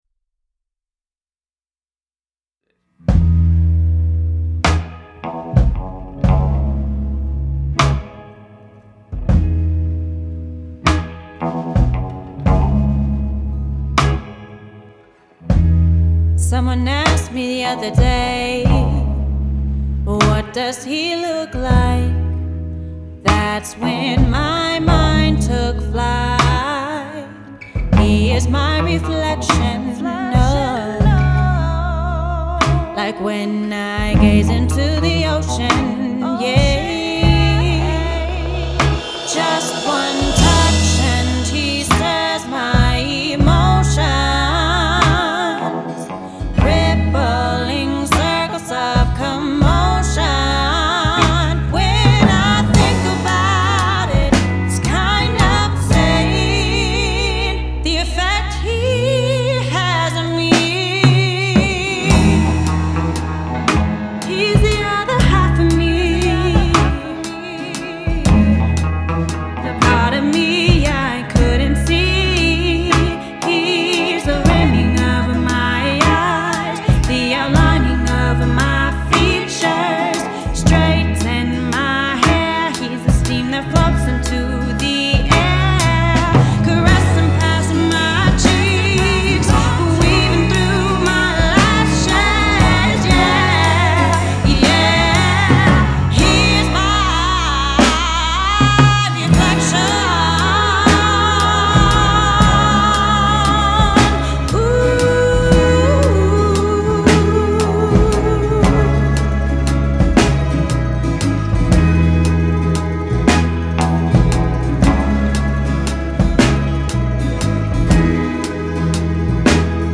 R&B, Soul, Ballad